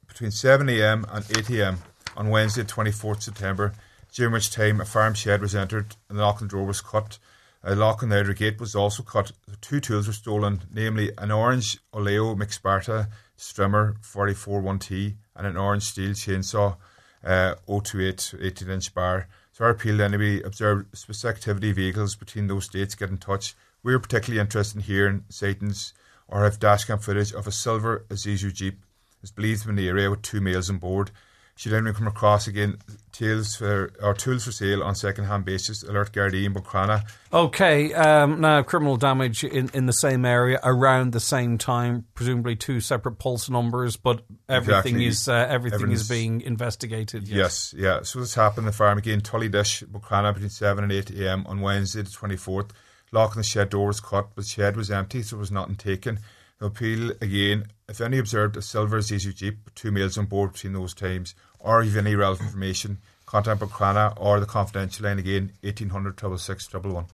made the following appeals on this morning’s Nine ’til Noon Show